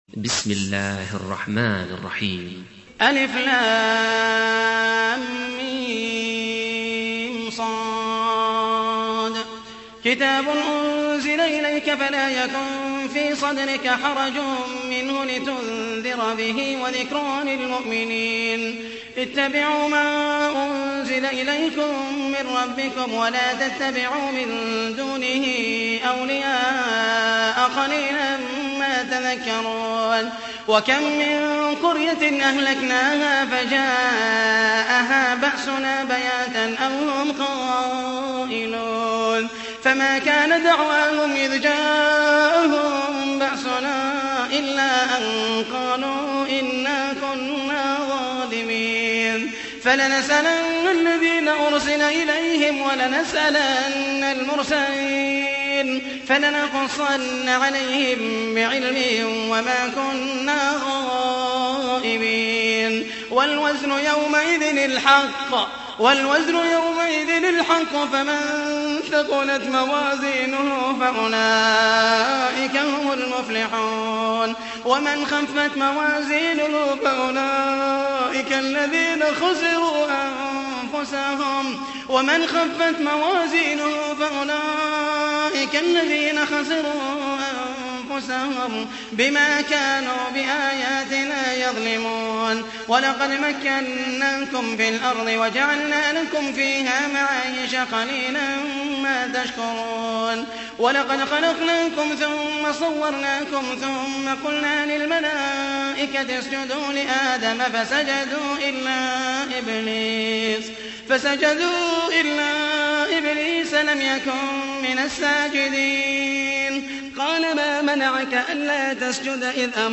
تحميل : 7. سورة الأعراف / القارئ محمد المحيسني / القرآن الكريم / موقع يا حسين